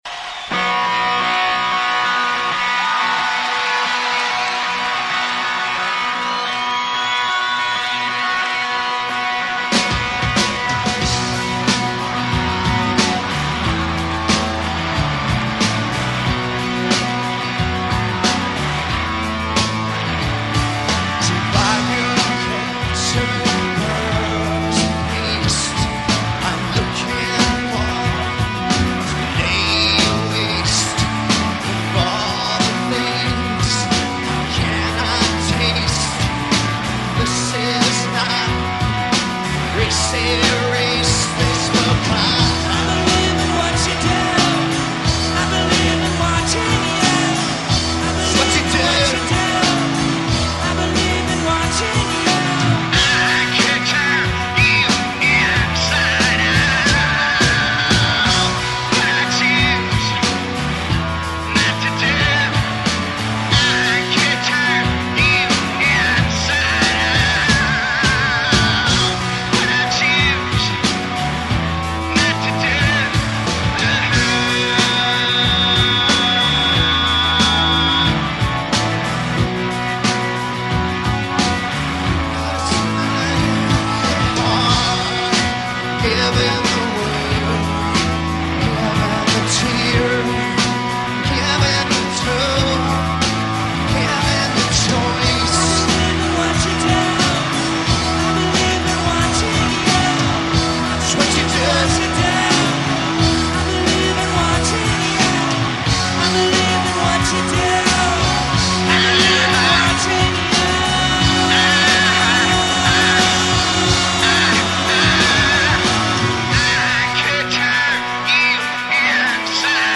bootleg